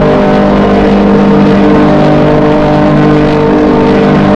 boxer-racing.wav